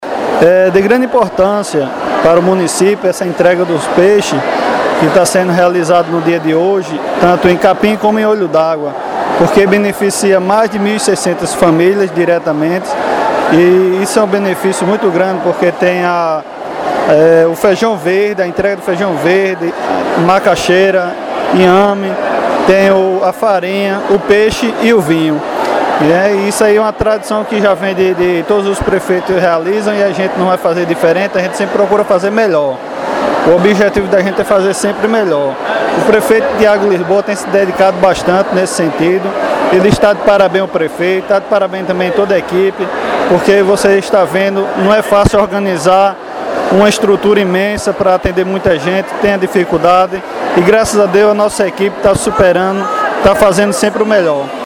Ouça áudio do vice-prefeito Erivelton: